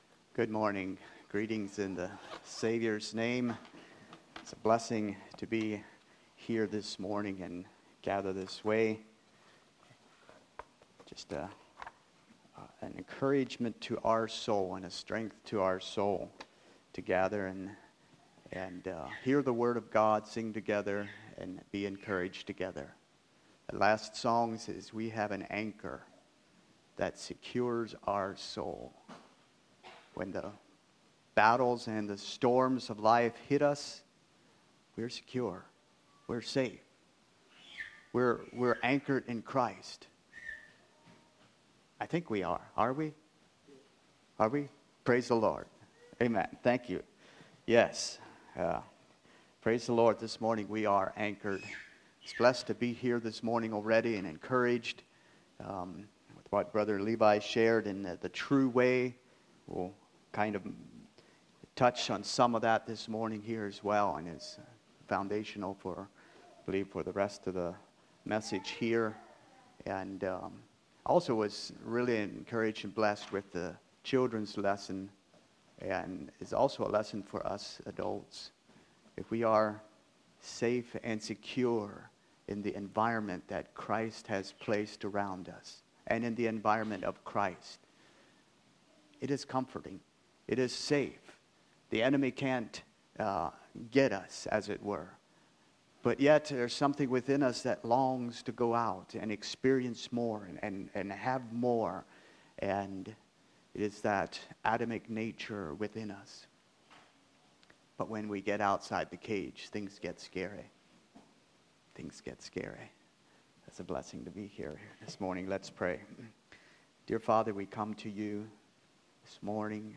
2024 Tent Meeting Messages